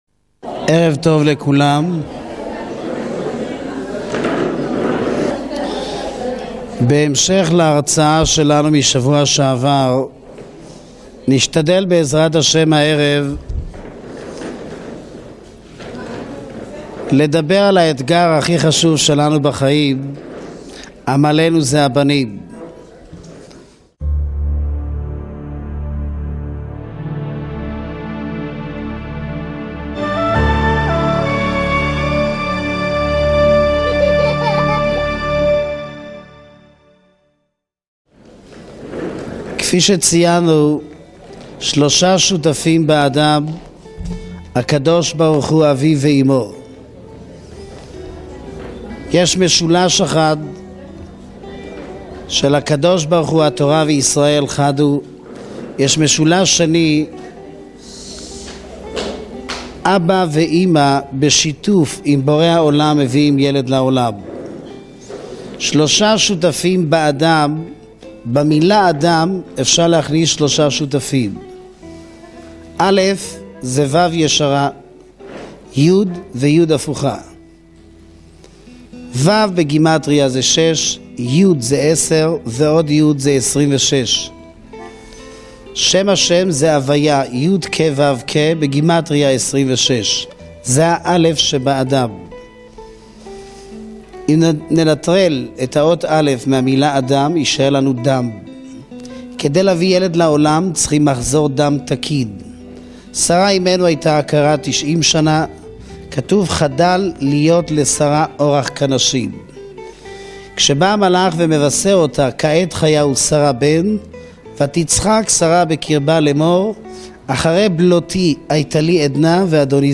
ילדים האתגר, דרשה מרתקת על חינוך ילדים